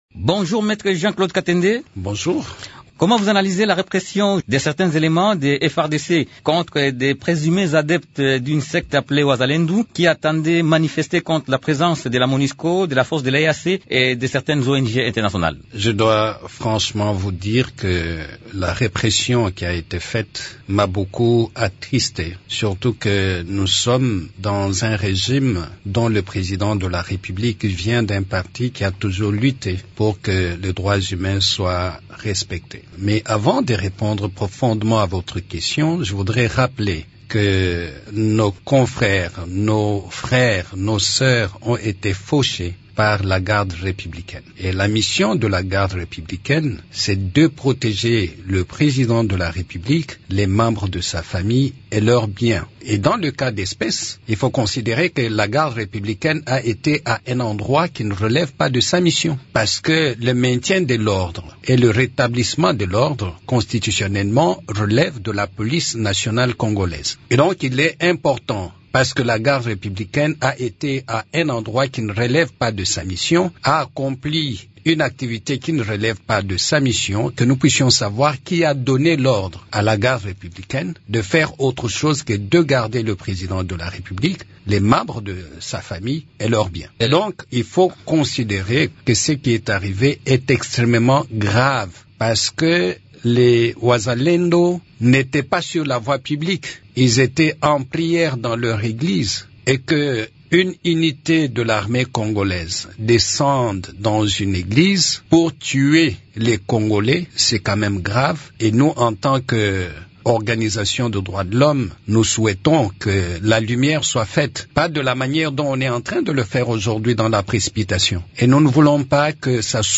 s’entretient avec